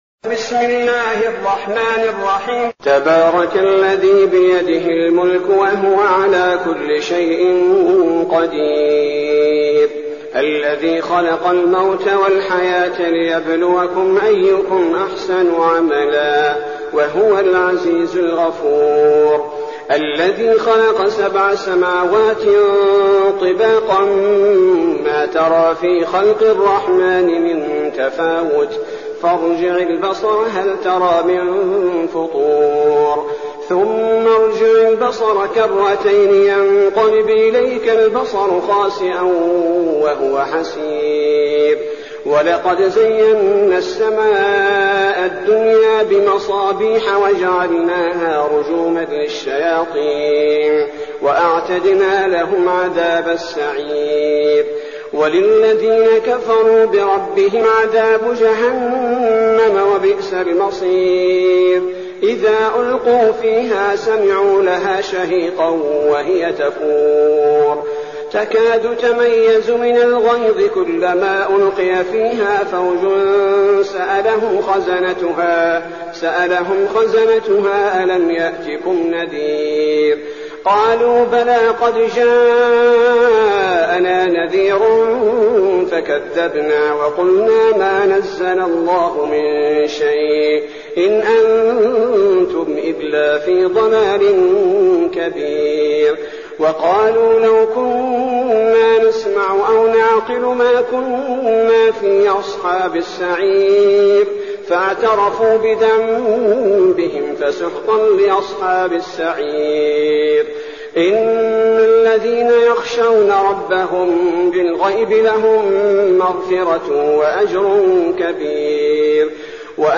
المكان: المسجد النبوي الشيخ: فضيلة الشيخ عبدالباري الثبيتي فضيلة الشيخ عبدالباري الثبيتي الملك